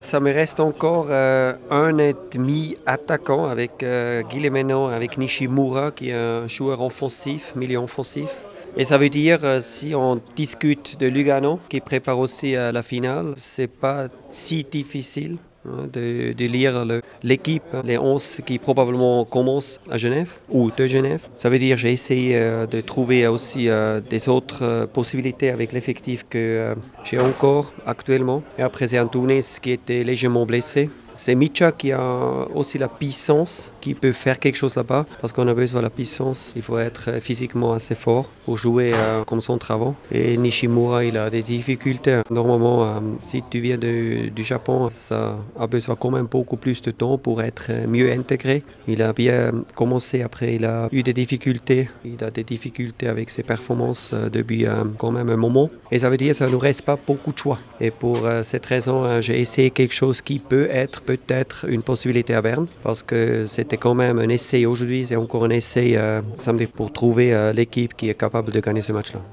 Le technicien zurichois nous explique ces choix dictés par un effectif plutôt restreint en cette fin de saison...